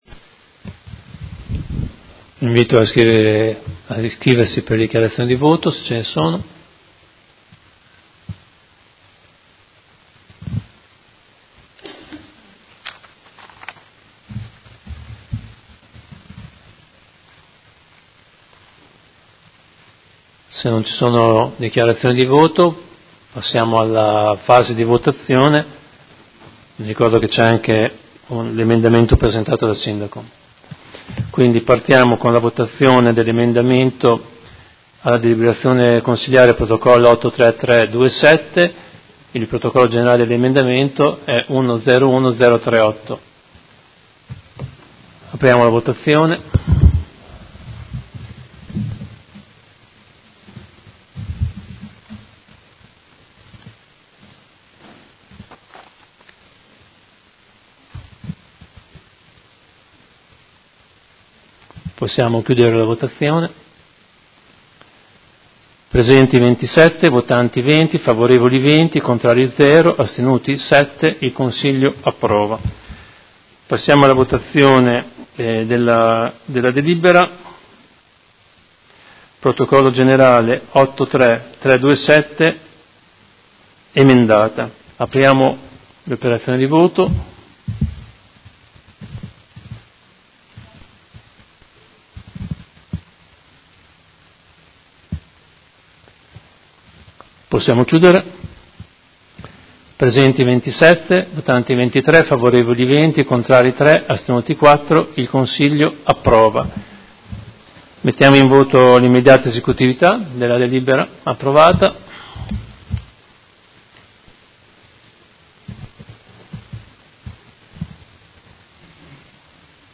Seduta del 04/04/2019 Come Presidente di turno. Mette ai voti l'emendamento nr. 101038: approvto.